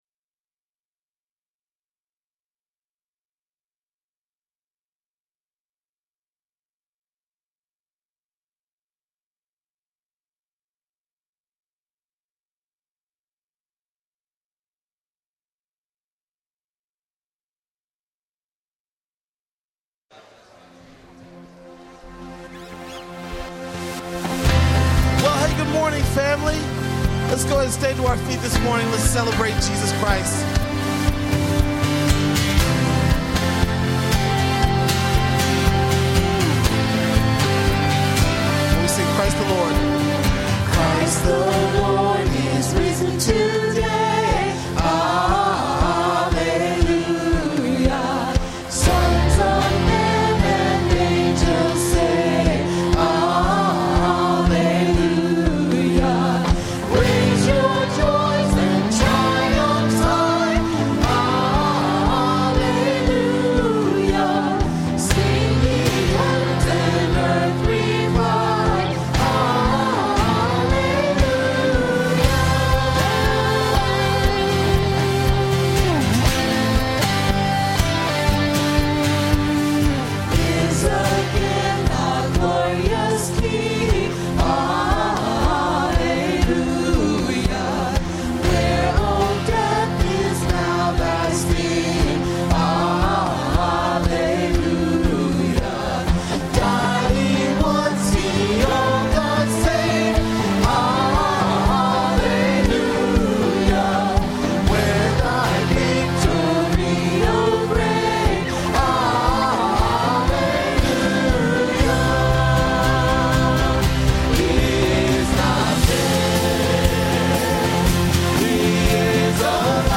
A message from the series "2024 Christmas Celebration."